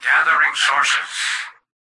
"Gathering sources" excerpt of the reversed speech found in the Halo 3 Terminals.